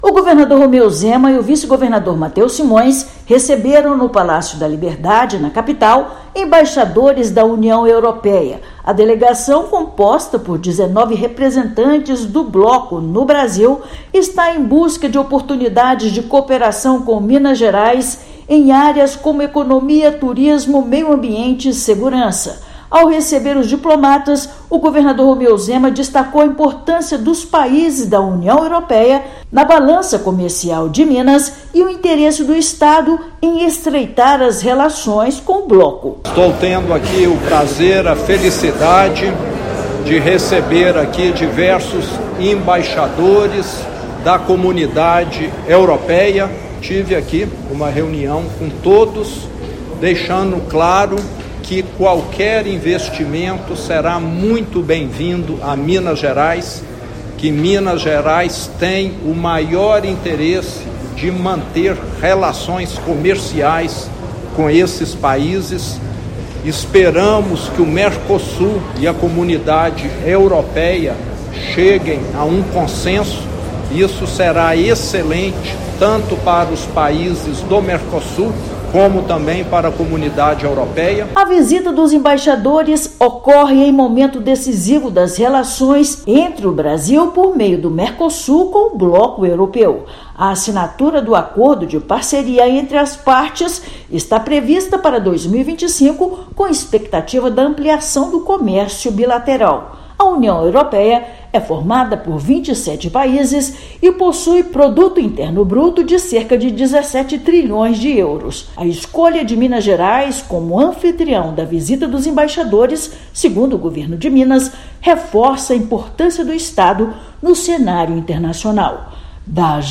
Encontro se dá em meio a negociações para parceria econômica entre Europa e América do Sul. Ouça matéria de rádio.